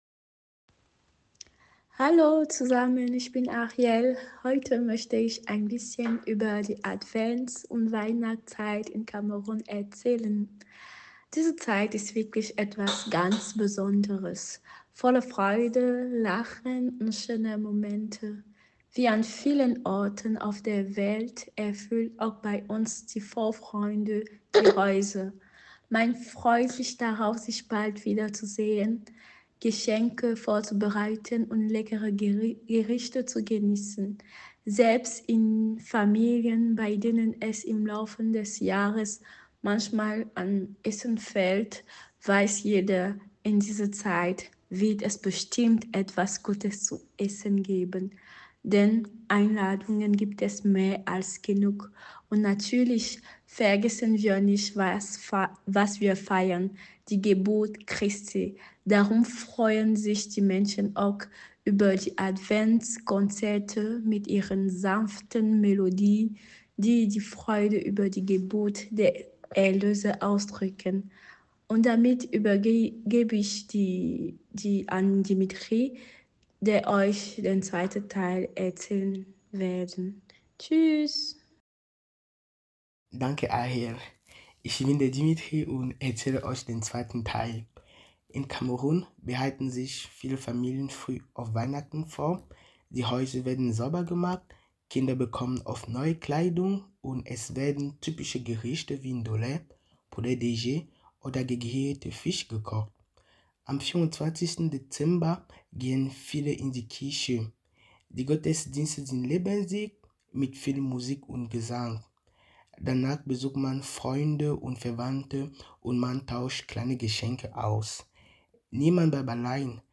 In unserem KHG-Adventskalender, der schon am 1. Advent beginnt, öffnen sich zu den Adventssonntagen die Türchen mit Stimmen unserer KHG-Mitglieder aus aller Welt. Sie teilen persönliche Erfahrungen, Zeichen der Hoffnung und kleine Impulse:  Wie sieht die Weihnachtsvorfreude anderswo aus?